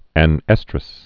(ănĕstrəs)